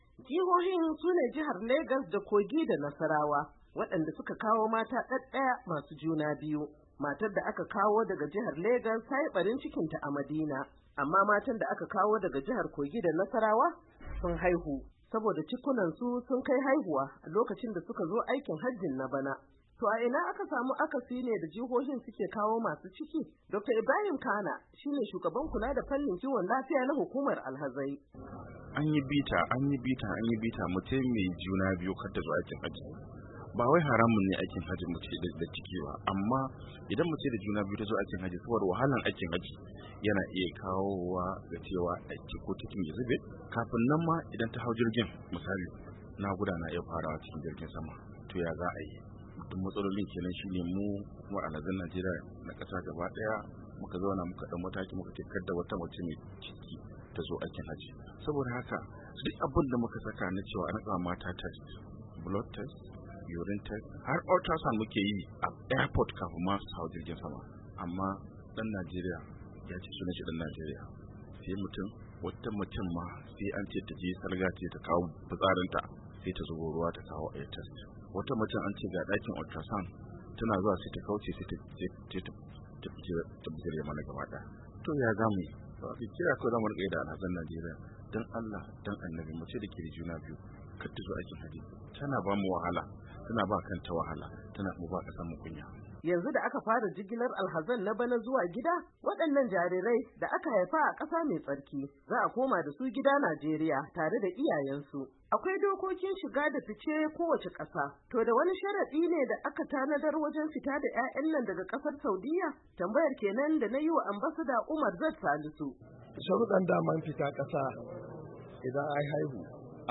Labari da Dumi-Duminsa